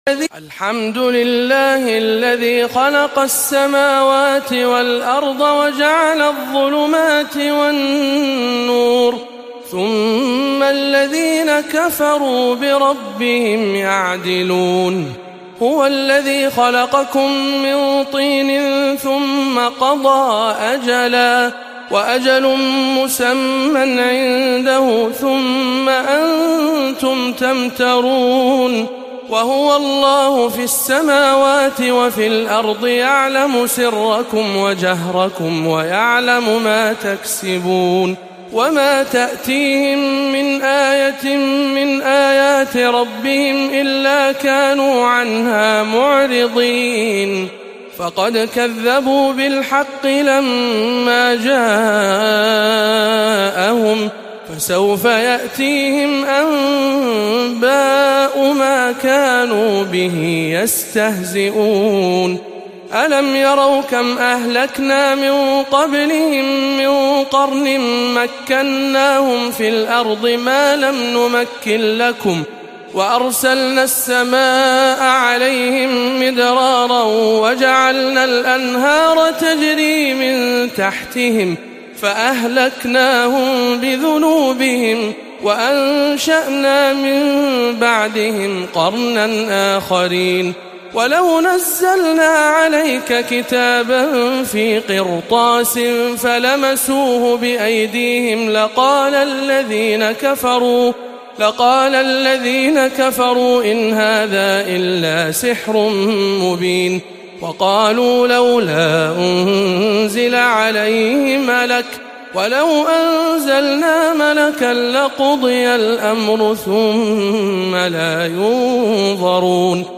04. سورة الأنعام بجامع أم الخير بجدة - رمضان 1438 هـ